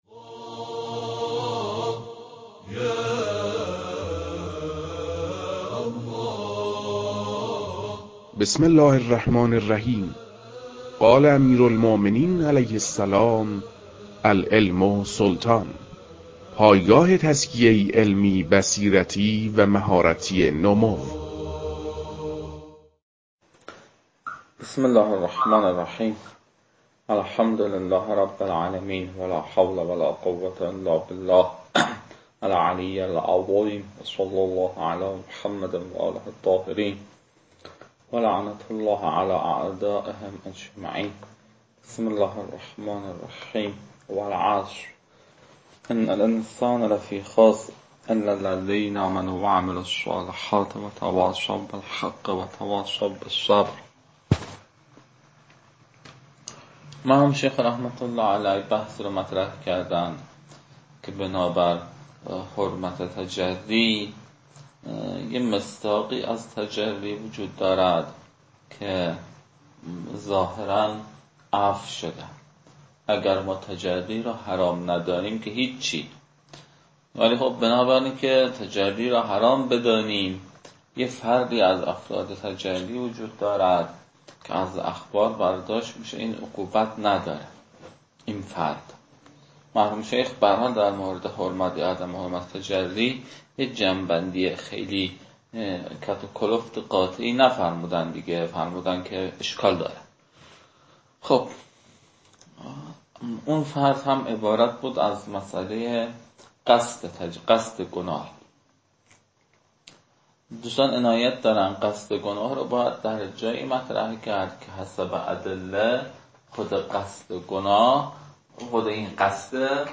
فایل های مربوط به تدریس مبحث رسالة في القطع از كتاب فرائد الاصول